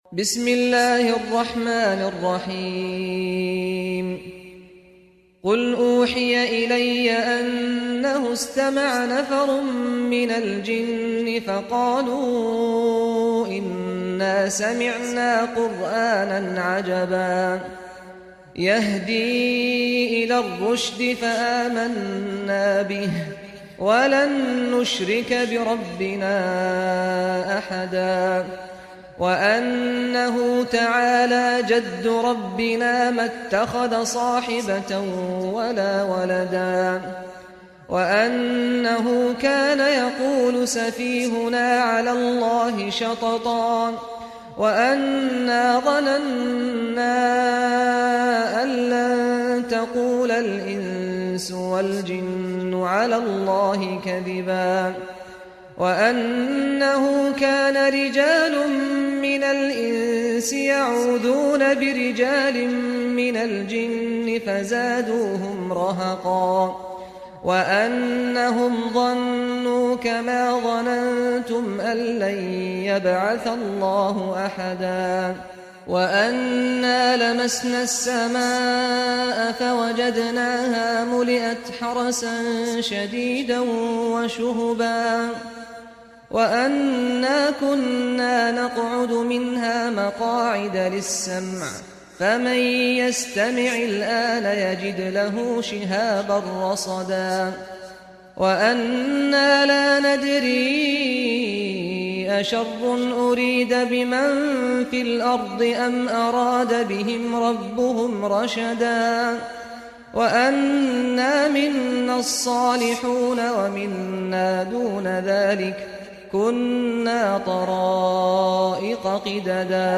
Surat Al Jenn Reciter Saad Al Ghamdi
Impressive and distinctive Rare and special Recitations